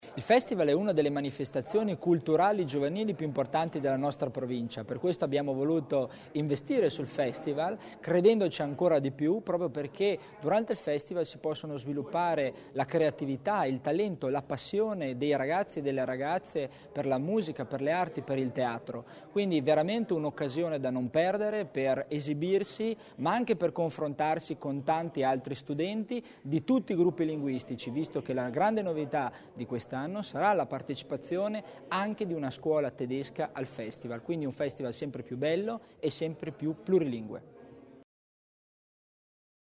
L’Assessore Tommasini illustra le novità del Festival studentesco